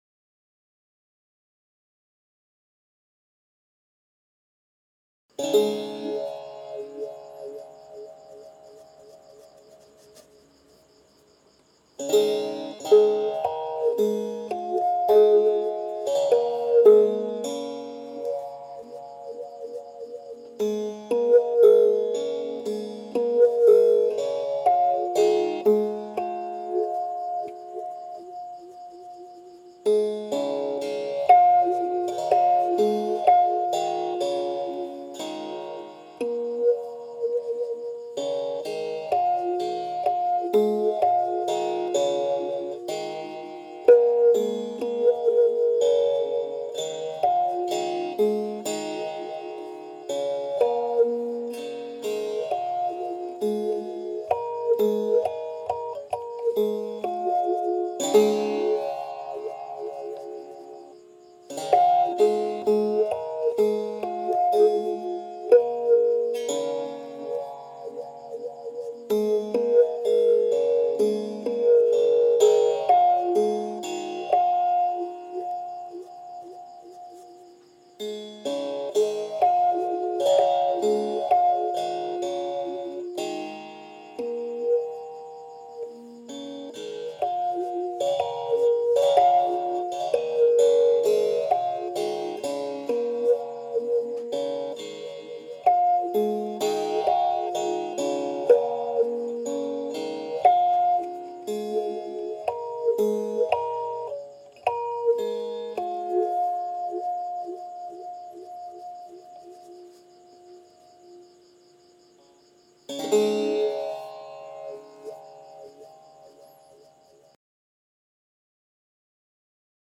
พิณเปี๊ยะ
คำอธิบาย : กล่าวกันว่า "พิณเปี๊ยะ" หรือ "เปี๊ยะ" เป็นเครื่องดีดตระกูลพิณที่ไพเราะ เสียงเบา และ เล่นยากที่สุดอย่างหนึ่งในบรรดาเครื่องดนตรีทั้งหมดทั้งมวล “โฮงซึงหลวง เมืองลอง แพร่” หนึ่งในผู้ร่วมโครงการฯ ได้รวมกลุ่มนักดนตรีอีสาน จะเป็นผู้บรรยายหลักและแสดงการละเล่นในการจัดทำสื่อวีดิทัศน์ในครั้งนี้
คำสำคัญ : เครื่องดนตรี, โฮงซึงหลวง, ล่องแม่ปิง, เมืองลอง, พิณ, ล้านนา, พื้นบ้าน, พิณเปี๊ยะ, แพร่